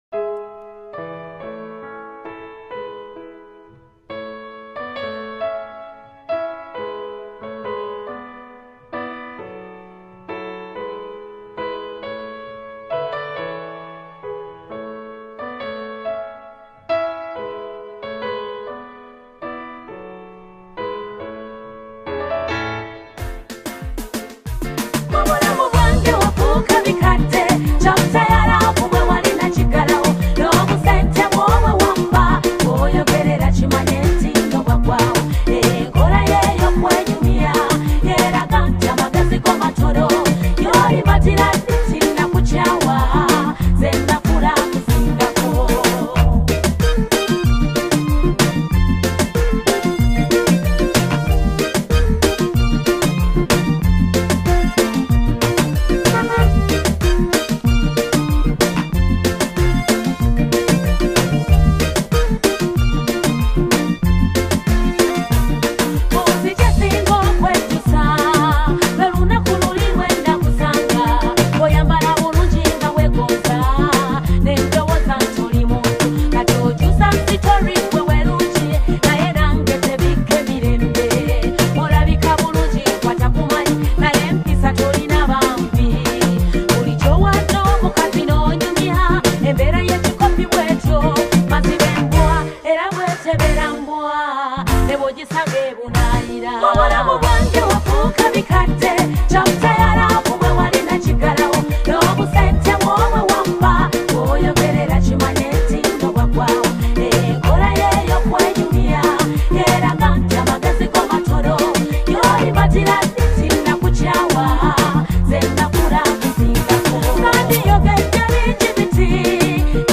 Genre: Band Music